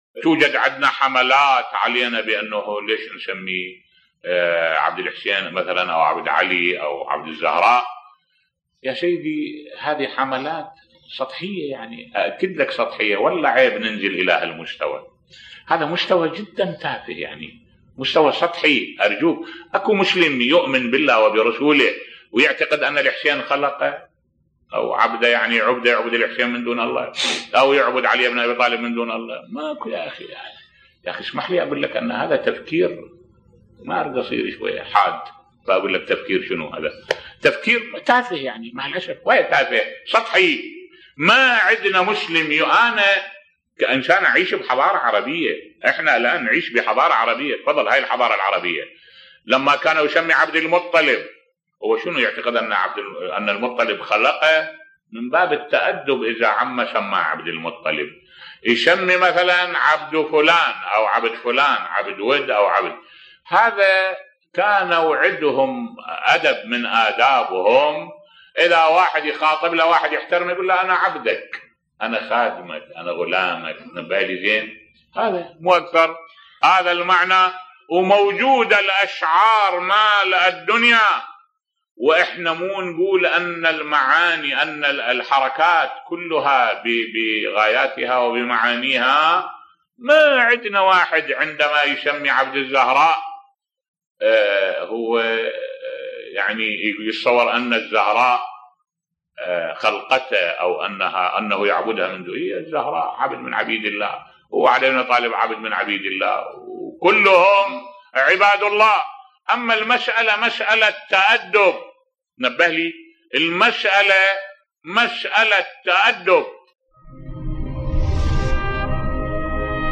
ملف صوتی لماذا نسمي بعض أبنائنا عبد الحسين أو عبد الزهراء بصوت الشيخ الدكتور أحمد الوائلي